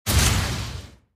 soceress_skill_icyfraction_02_finish.mp3